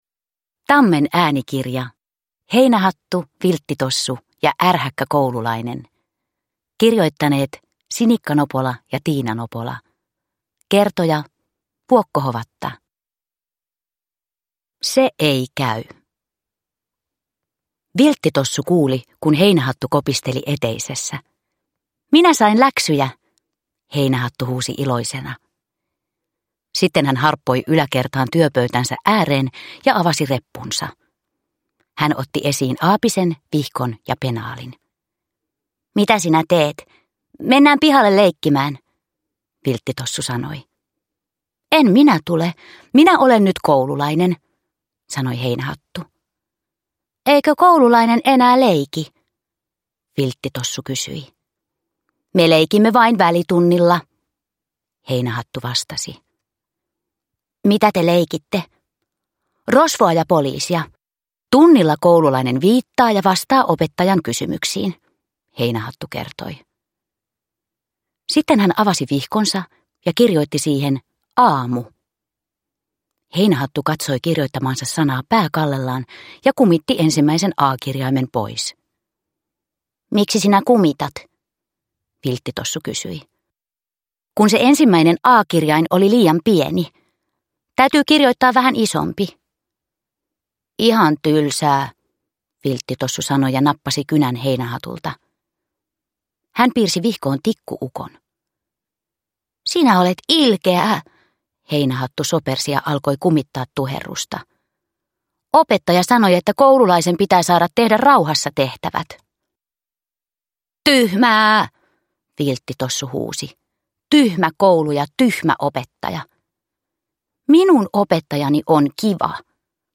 Heinähattu, Vilttitossu ja ärhäkkä koululainen – Ljudbok – Laddas ner